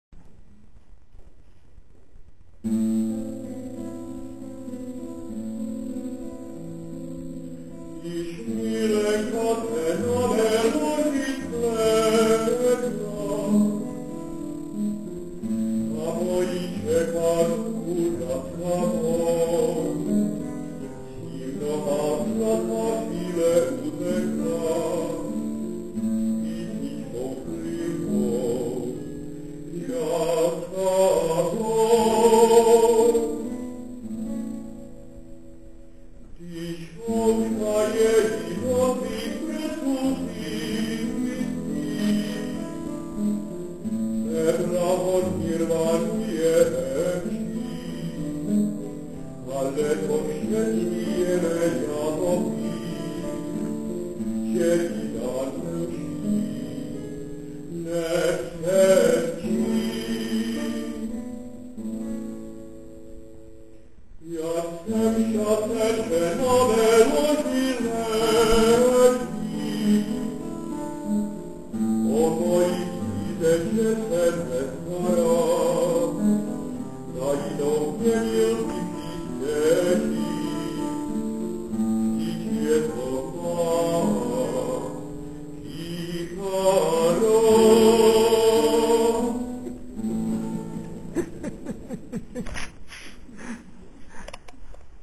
27. komorní koncert na radnici v Modřicích
Ukázkové amatérské nahrávky WMA:
Když milenka se na mém loži svléká, zpěv
kytara